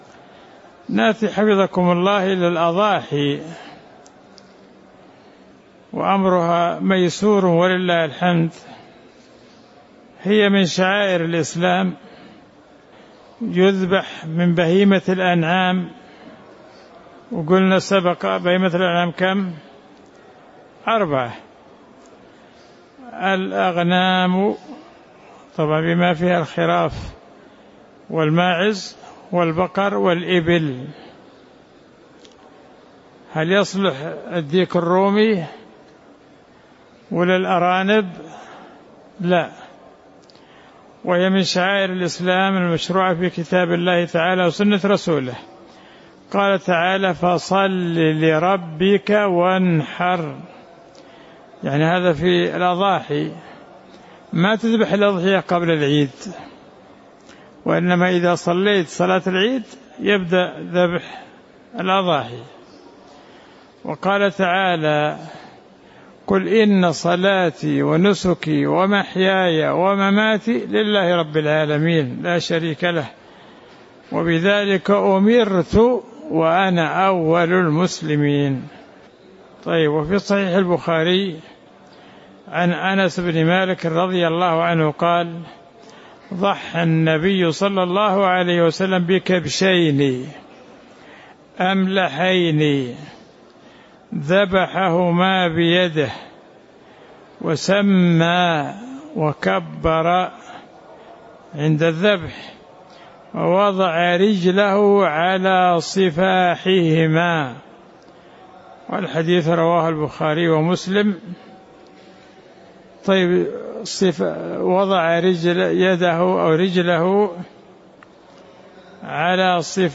تاريخ النشر ٧ ذو الحجة ١٤٤٠ المكان: المسجد النبوي الشيخ